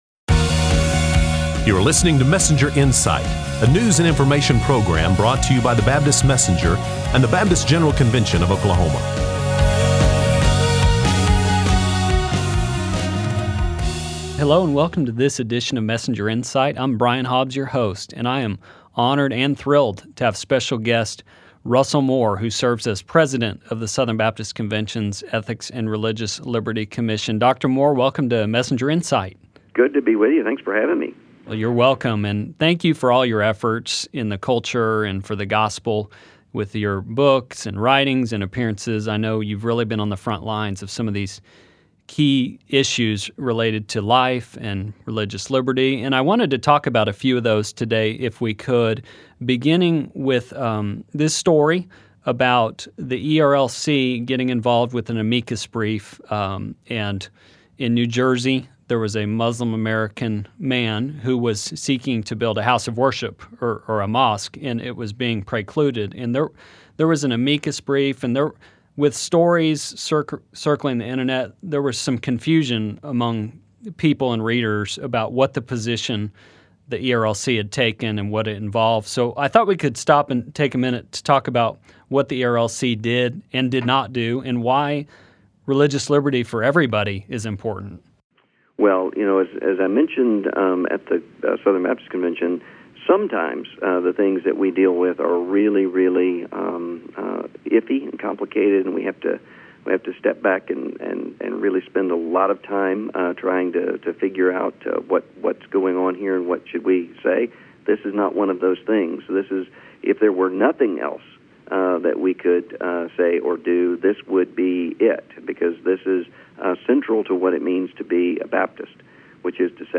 Interview with Russell Moore Dr. Moore discusses religious liberty, a SCOTUS ruling and more.